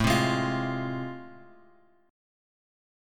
A Minor Major 7th Sharp 5th